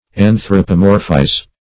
Anthropomorphize \An`thro*po*mor"phize\, v. t. & i.